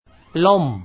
ล่ม  lohmF
pronunciation guide
Royal Thai General Systemlom